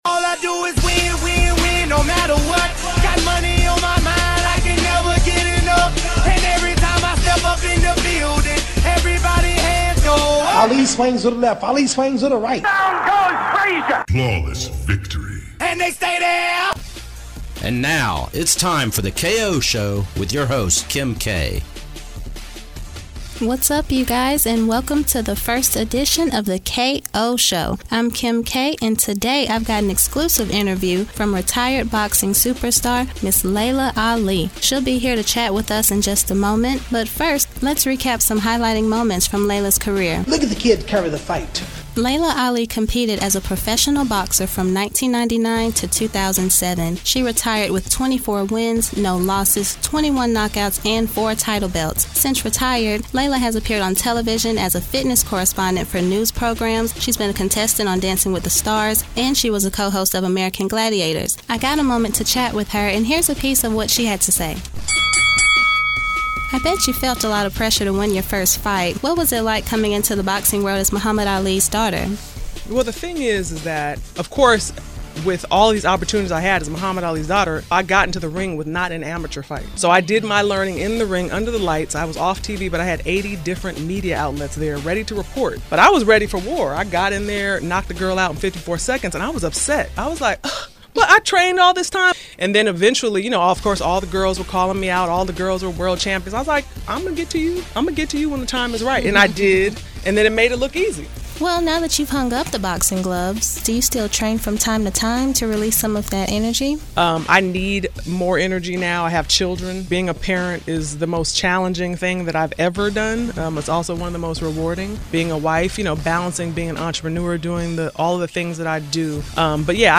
Radio Interview
Southern accent
Young Adult